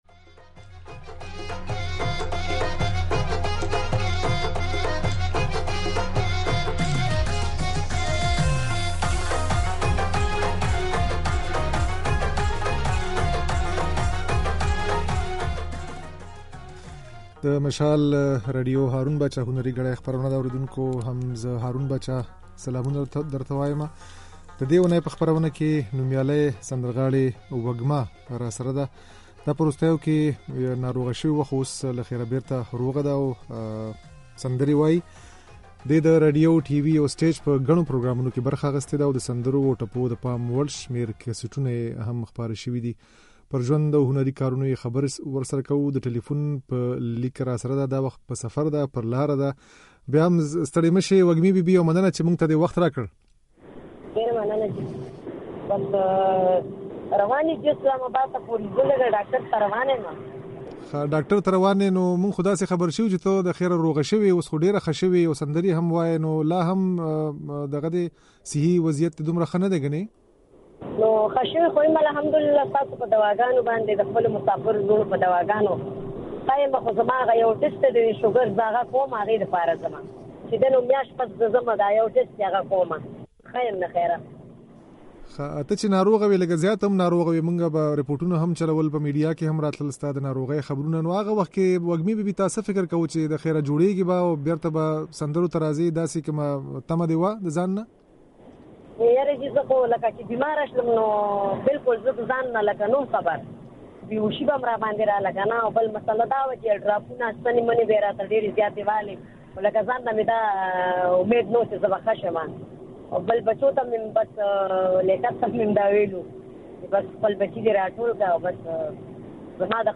د "هارون باچا هنري ګړۍ" د دې اوونۍ خپرونې ته مو نوموتې سندرغاړې وږمه مېلمنه کړې وه.
دې له يوې اوږدې مودې ناروغۍ وروسته يو وارې بیا سندرې ويل پيل کړي دي. نوموړې په عين وخت کې ګيله منه ده چې د پاکستان حکومت سږکال هم د دې نوم د صدارتي اېوارډ لپاره غوره نه کړ. د وږمې دا خبرې او ځينې سندرې يې د غږ په ځای کې اورېدای شئ.